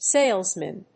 • / ˈselzmɪn(米国英語)
• / ˈseɪlzmɪn(英国英語)
salesmen.mp3